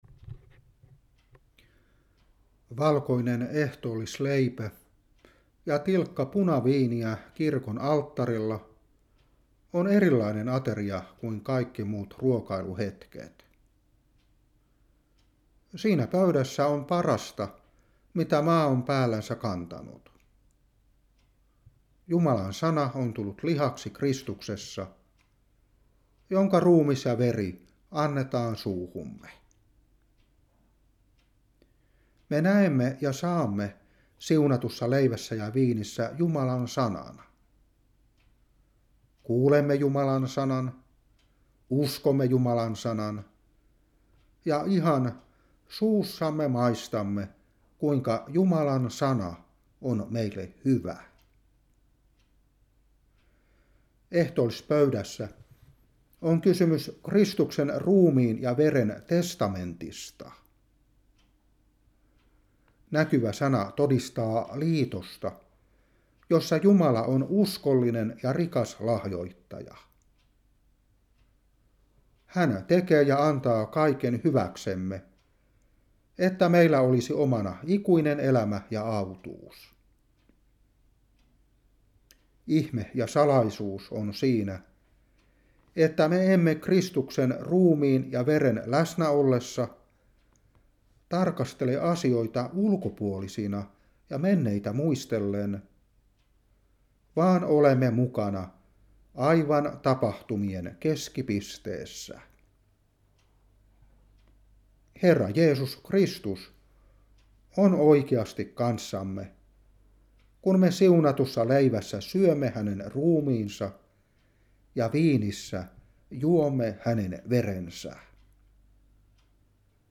Opetuspuhe 2022-4.